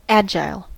agile: Wikimedia Commons US English Pronunciations
En-us-agile.WAV